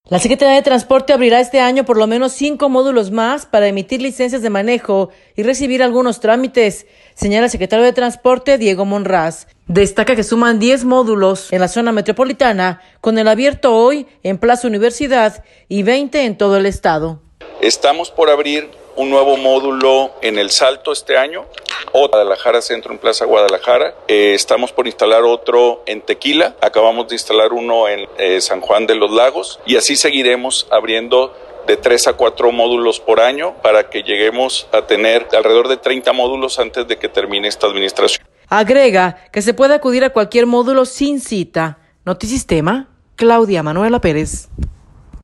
La Secretaría de Transporte abrirá este año por lo menos cinco módulos más para emitir licencias de manejo y recibir algunos trámites, señala el secretario de Transporte, Diego Monraz.